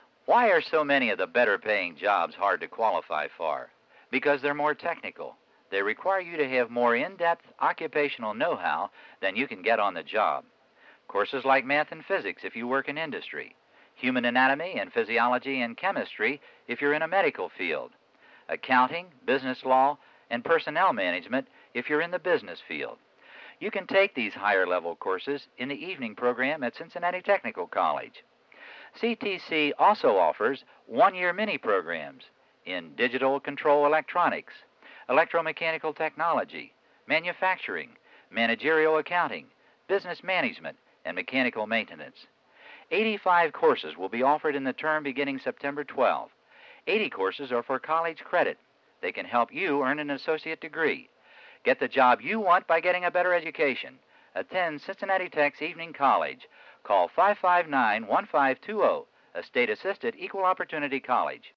The audio tracks below are examples of radio advertising produced by the college in the 1970s. This series of ads, directed toward non-traditional students, promotes the availability of evening courses at CTC.